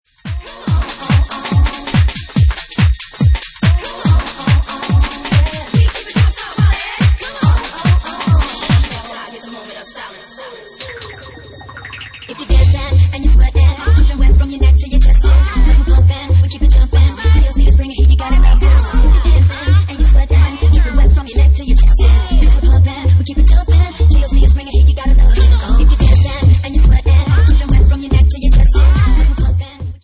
Genre:Bassline House
Bassline House at 145 bpm